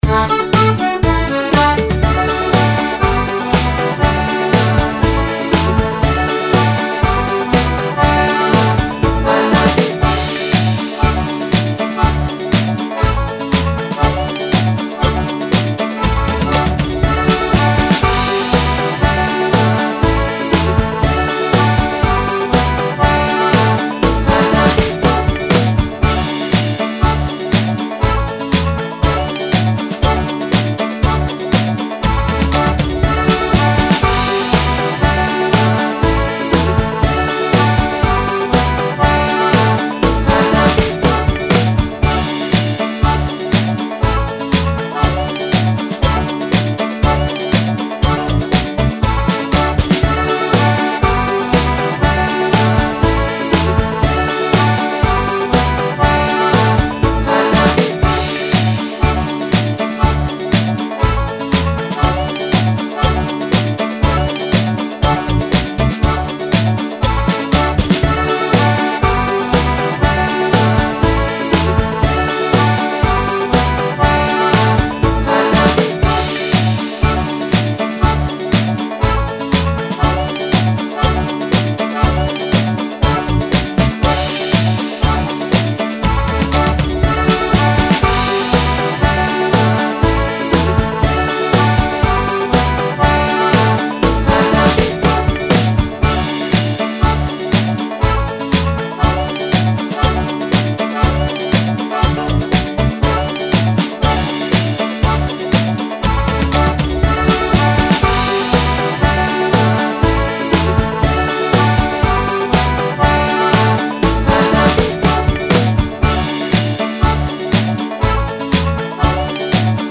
Und zum Mitsingen gibt es noch ein Halbplayback, das hier zu finden ist.
Kaptain-Romeo-Playback.mp3